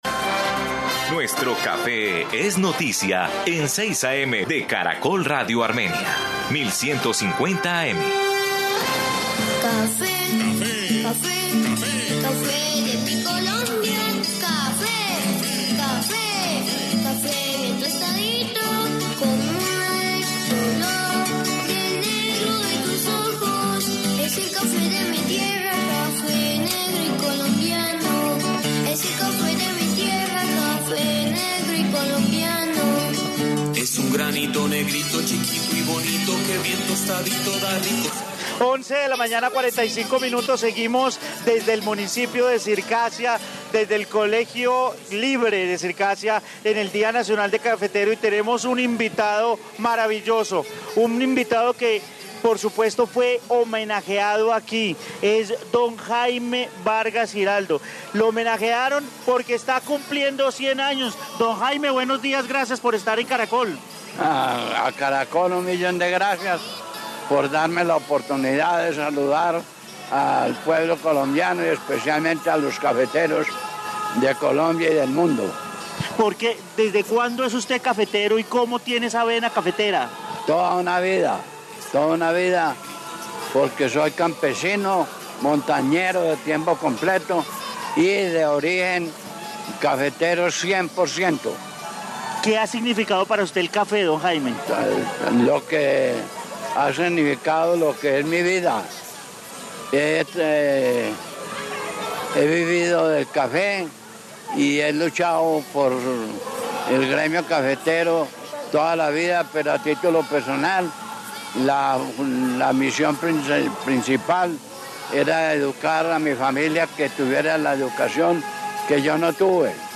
En el municipio de Circasia, en el coliseo del colegio Libre de Circasia en el Día Nacional de Cafetero en la fiesta del caficultor entregaron varios reconocimientos a cafeteros destacados de Circasia y Montenegro.